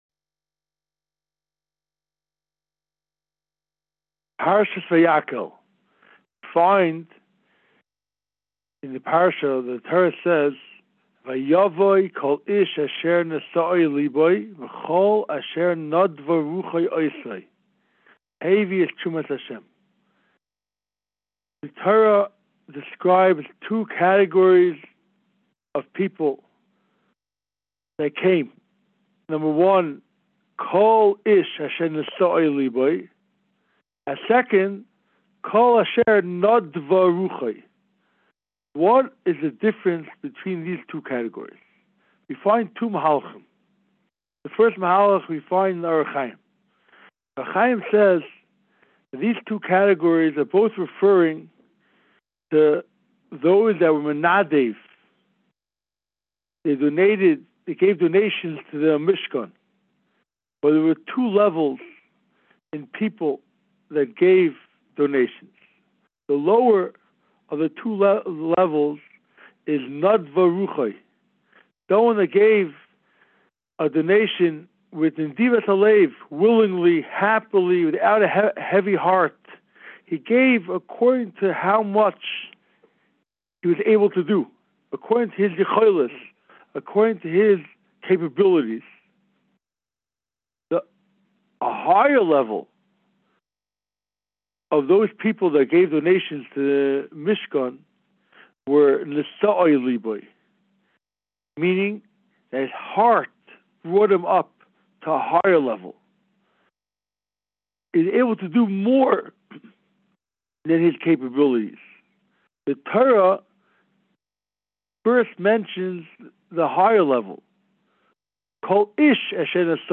Inspiring Divrei Torah, Shiurim and Halacha on Parshas Vayakhel from the past and present Rebbeim of Yeshivas Mir Yerushalayim.